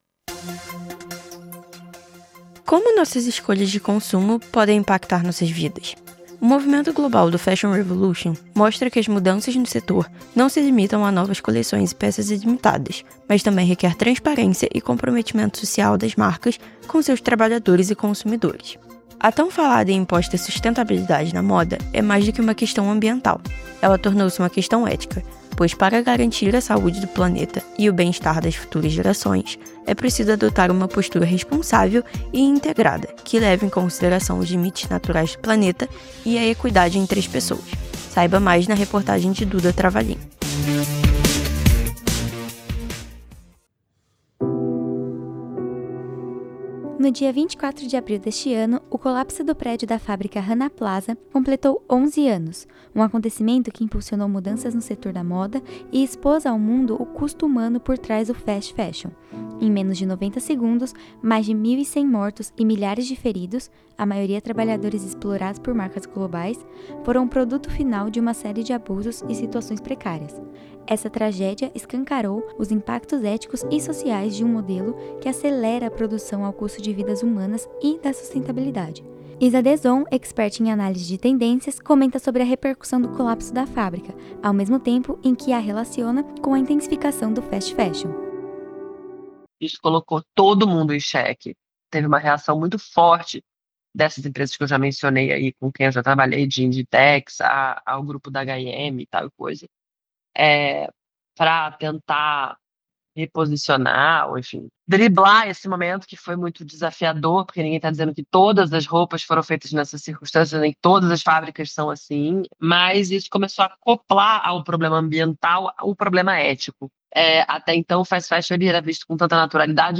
Áudio-reportagem realizada para disciplina de Rádio I